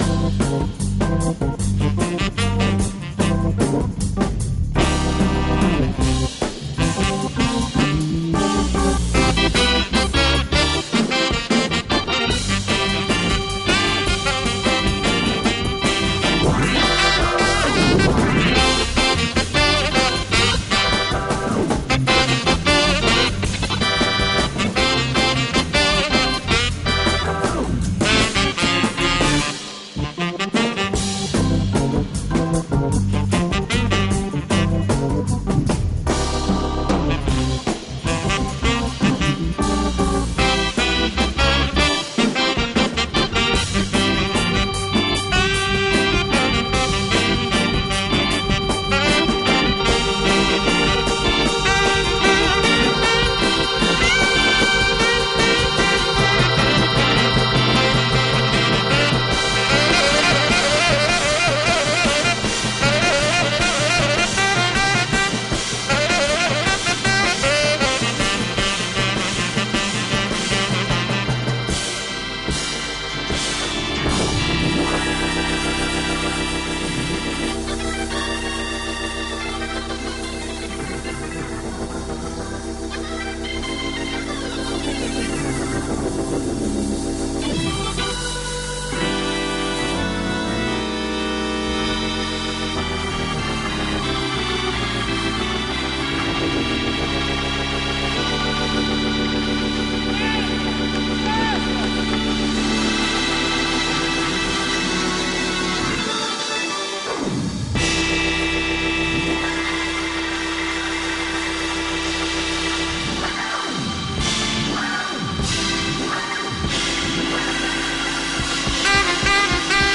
soul-jazz